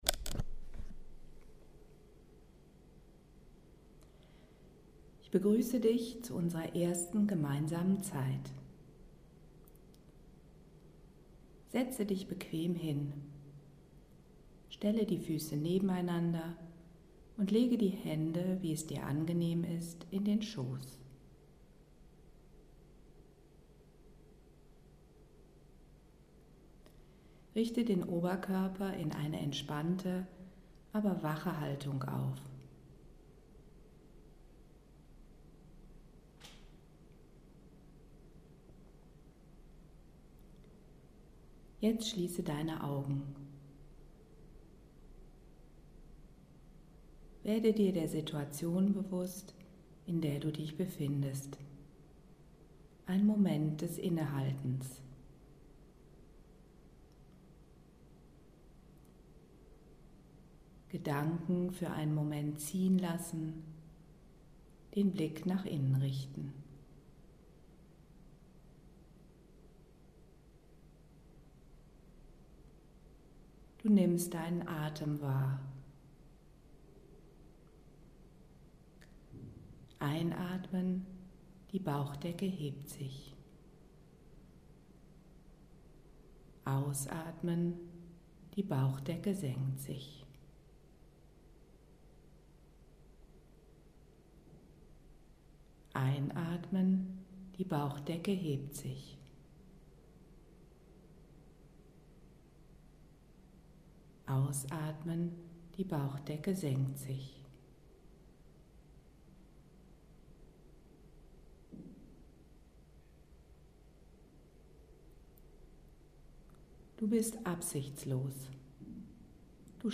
Meditation Das innere Lächeln R Das Leben ist gut Ich freue mich, dass du dir diese Zeit schenkst – ein erster Schritt. Halte an, suche dir eine Platz, an dem du dich wohl fühlst und für dich bist und lasse dich von dieser geführten Meditation mitnehmen.